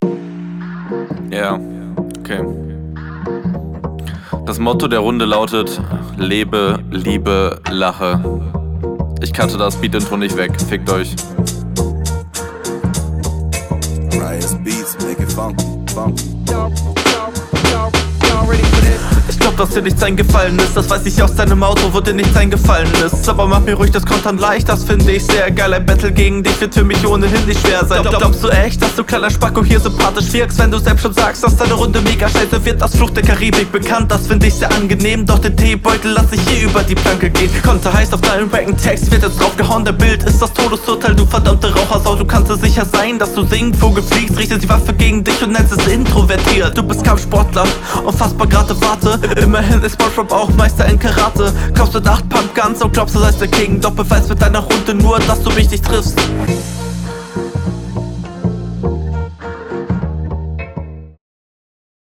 Hier haben wir leider ein ähnliches Problem wie in der HR, die Aussprache klingt teilweise …